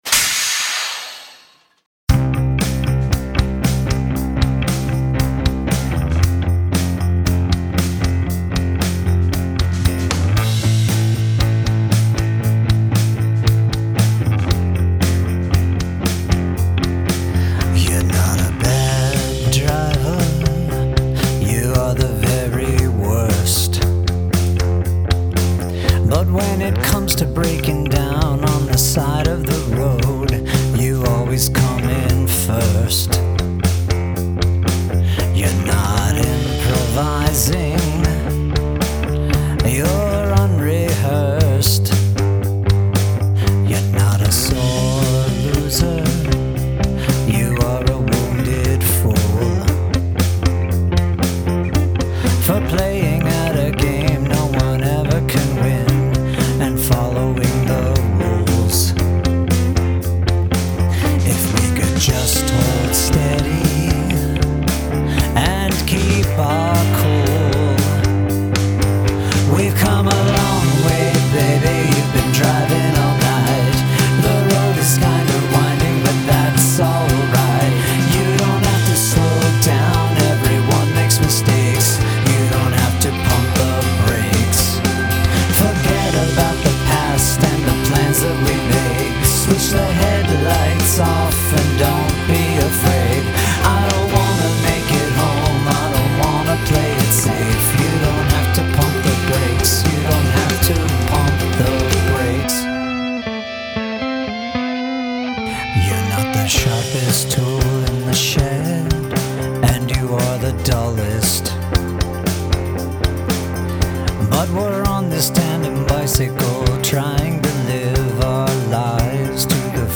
Diss Track
The tune is so good, and the voice is just the right timbre.
Such a great melody and general vibe.
The music was driving & appropriate for the story.
Great catchy tune--it feels very iconic.